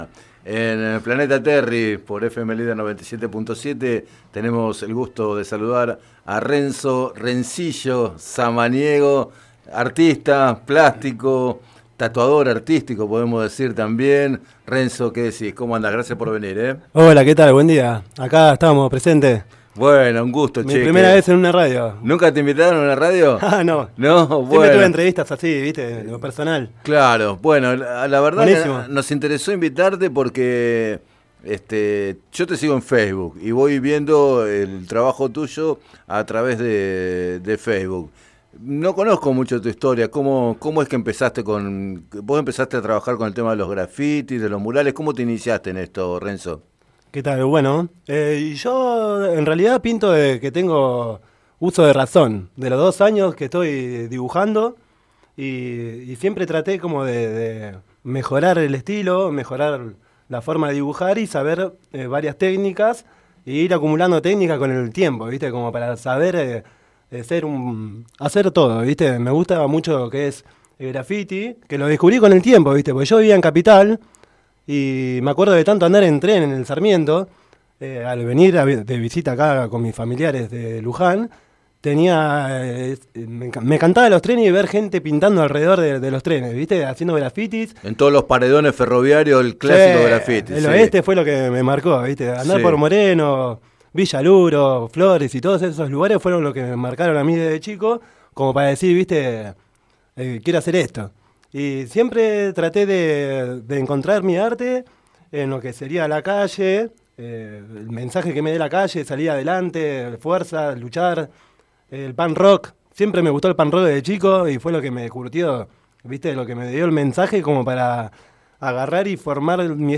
Entrevistado en Planeta Terri